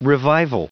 Prononciation du mot revival en anglais (fichier audio)
Prononciation du mot : revival